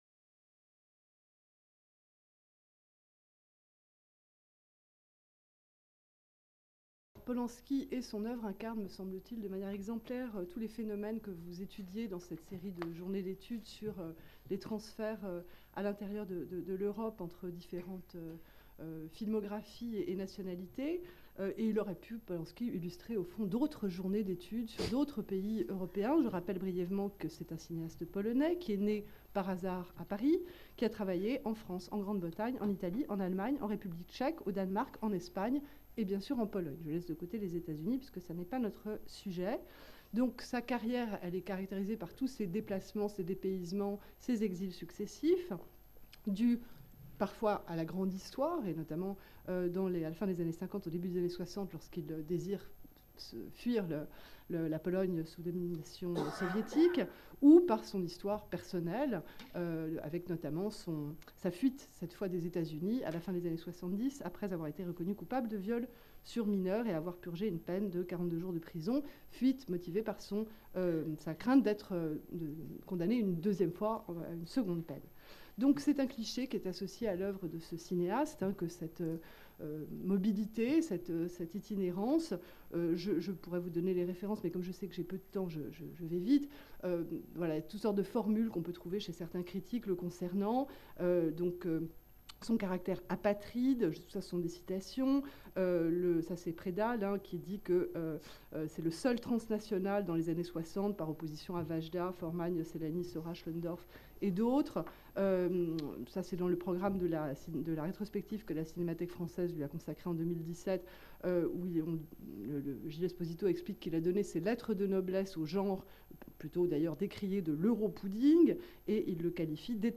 Cette communication a été filmée dans le cadre d'une journée d'étude du LASLAR consacrée au cinéma britannique.